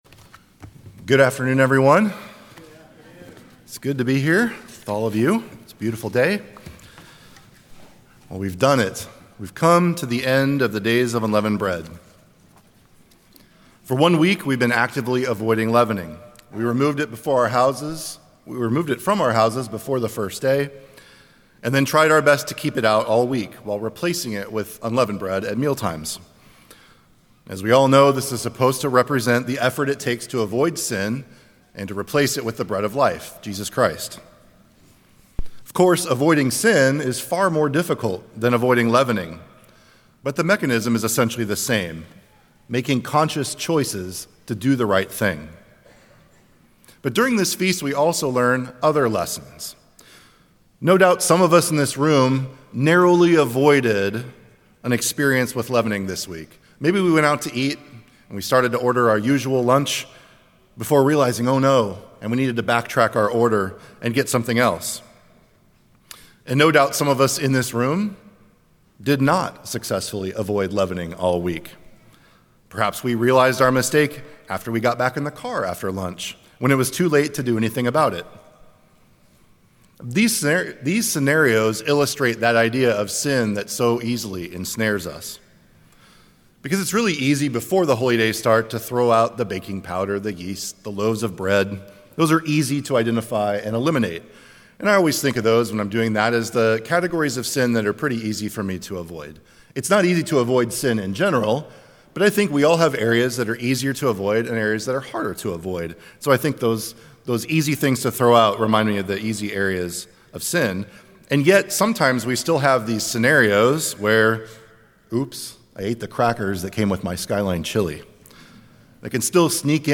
This sermon focuses on what to do when we stumble in our effort to overcome sin. Using the Days of Unleavened Bread as a backdrop, it explains that God doesn’t expect perfection—but persistence. The difference between the righteous and the wicked isn’t whether they fall, but whether they get back up.